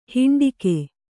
♪ hiṇḍike